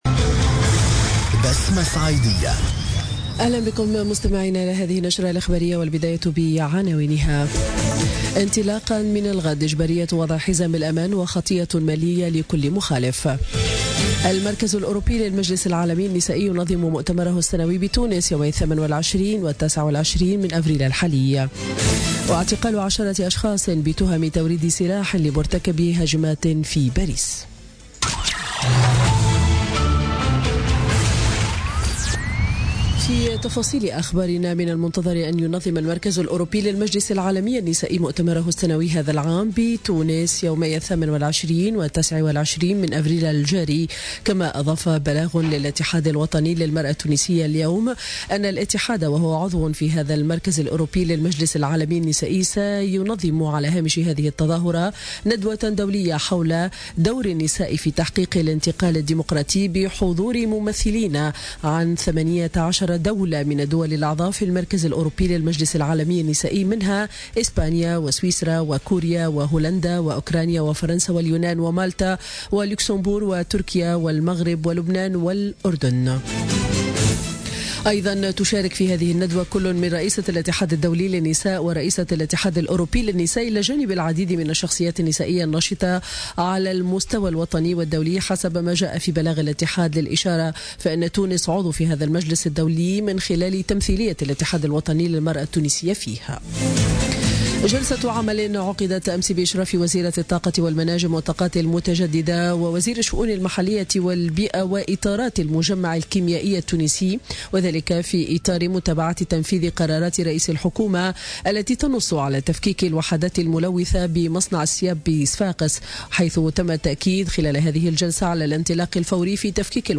نشرة أخبار منتصف النهار ليوم الإربعاء 26 أفريل 2017